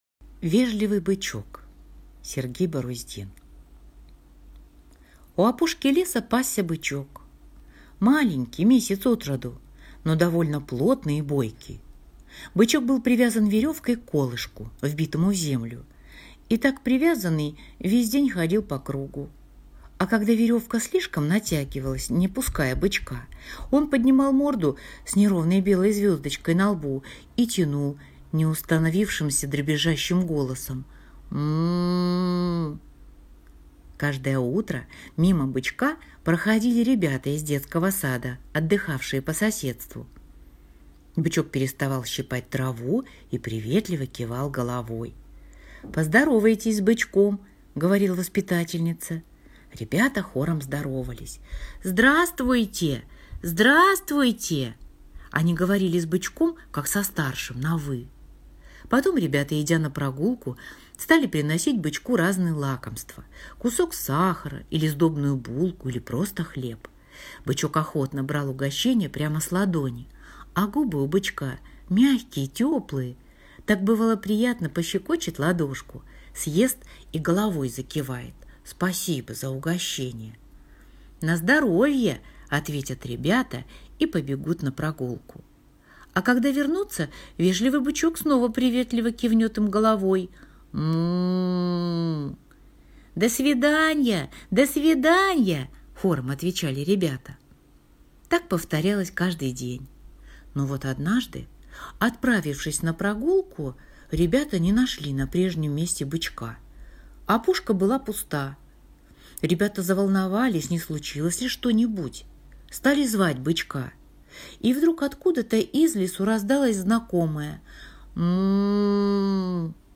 Аудиорассказ «Вежливый бычок»